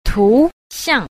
8. 圖象 – tú xiàng – đồ tượng (đồ hình)